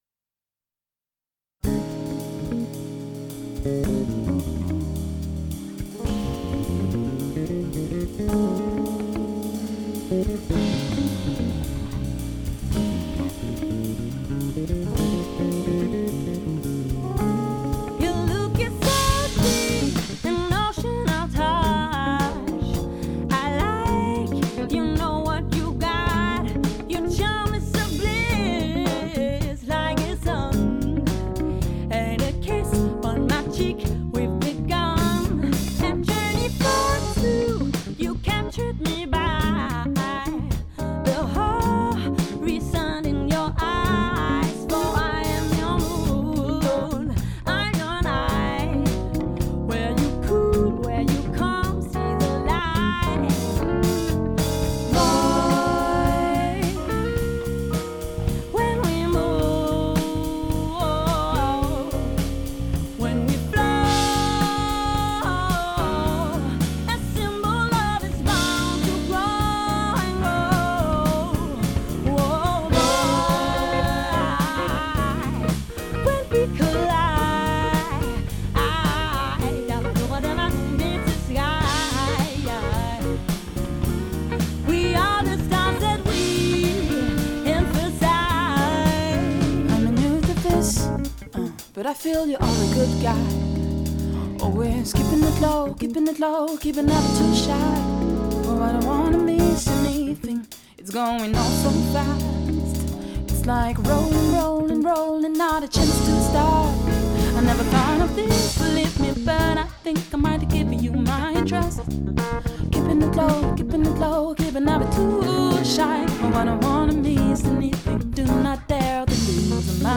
guitare
Guitariste de jazz à la sensibilité rare
piano
batterie
basse